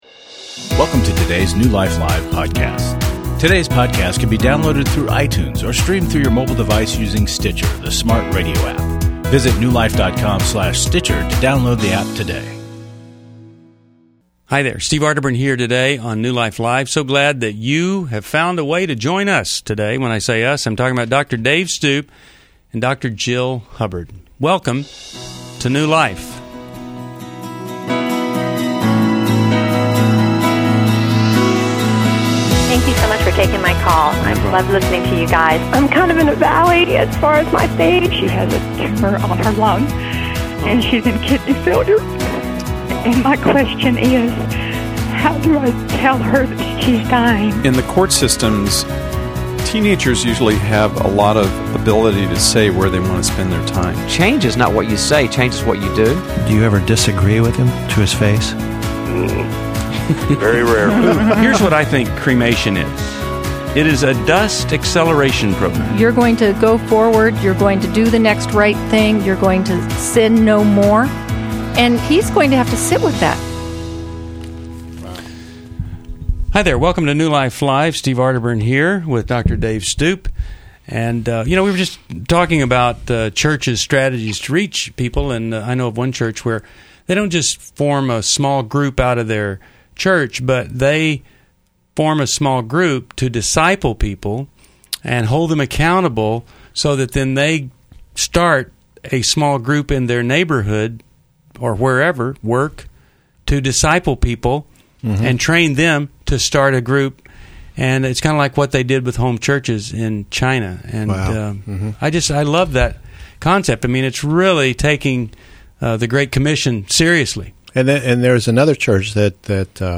Explore marriage, abuse, and overcoming emotional challenges in this episode of New Life Live as callers seek guidance on love and family dynamics.